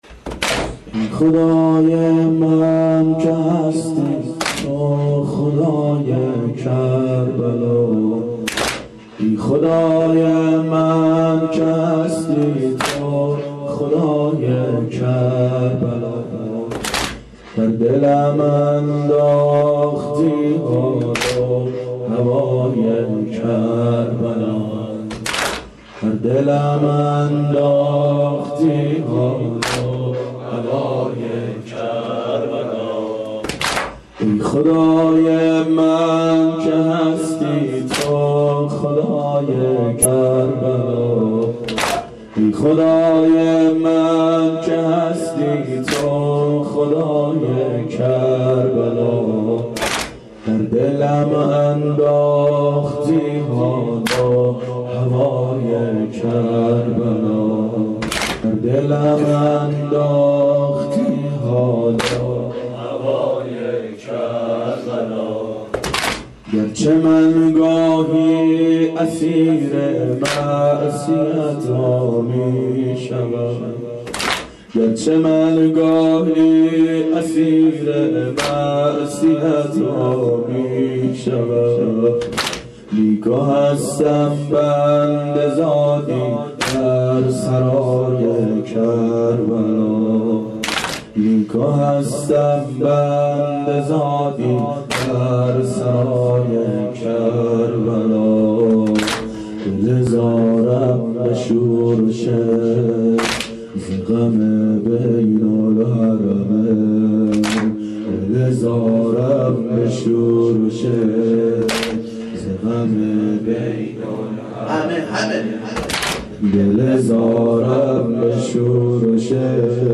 شب عاشورا هیئت عاشقان اباالفضل علیه السلام